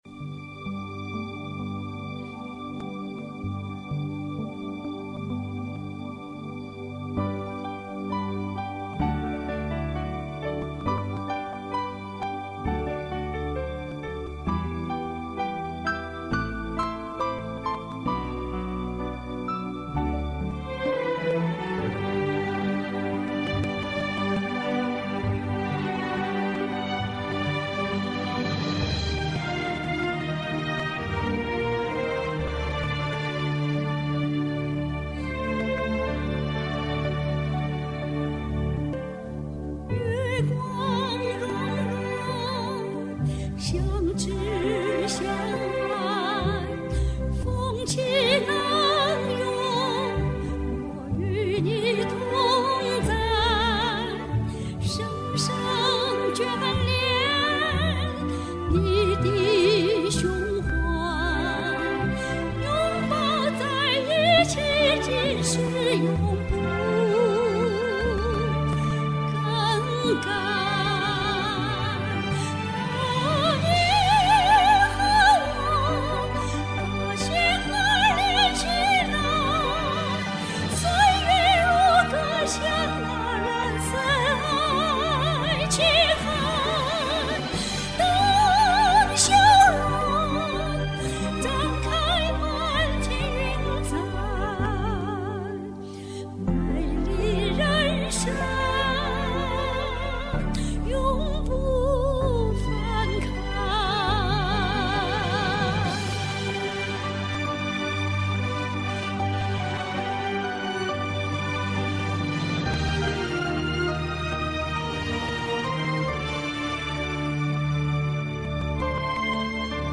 唱的不好